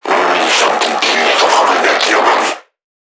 NPC_Creatures_Vocalisations_Infected [63].wav